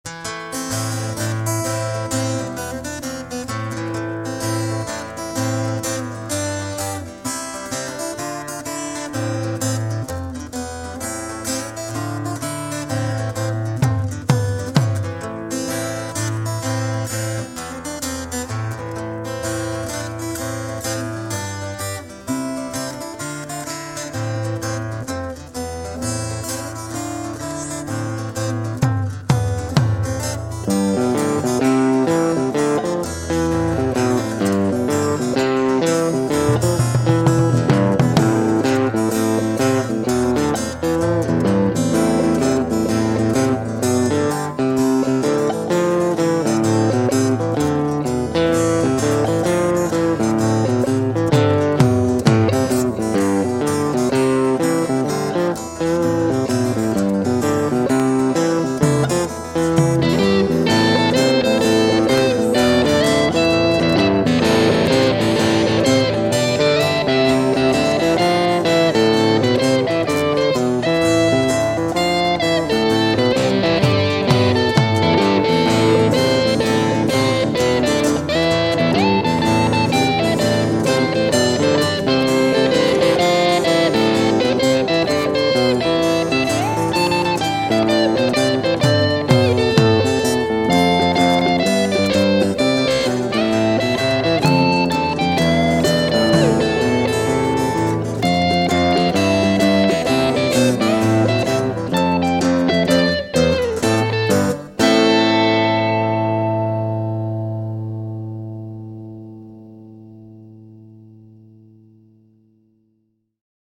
Télécharger: Ogg Vorbis (1.9 MB) MP3 (1.8 MB) Instrumental.